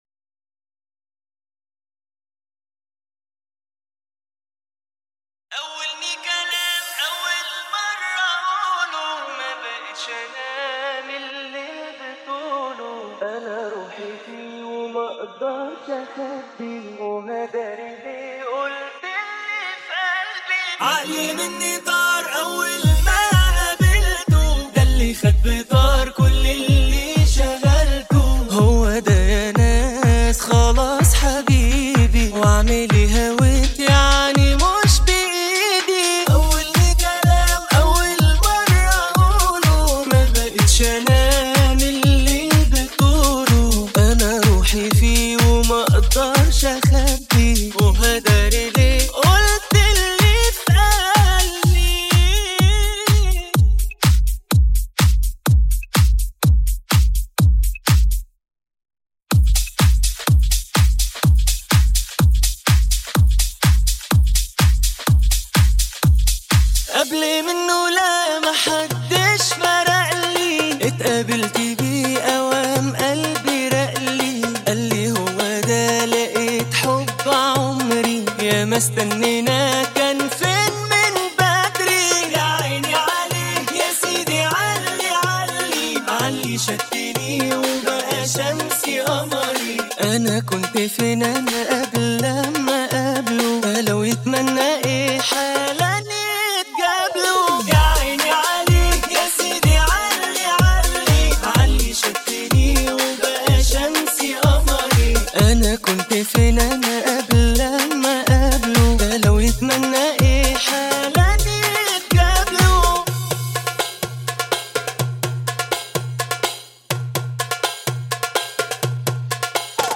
أغانيك المفضلة بدون المعازف الموسيقية